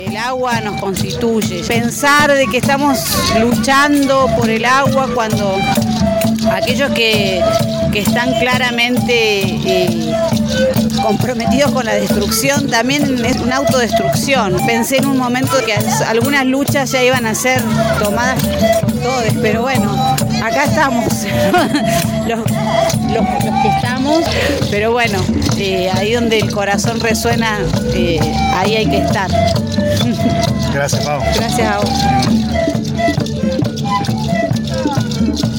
Caminata artística por el agua
El día 22 de marzo, en el marco del Día Mundial del Agua, se realizó la “Caminata Artística por el Agua”.